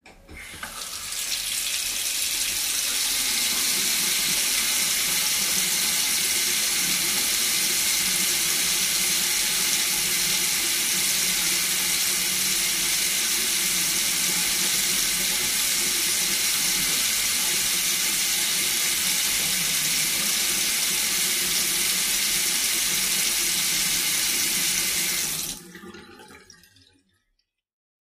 fo_sink_rundrainopen_02_hpx
Bathroom sink water runs with drain open and closed.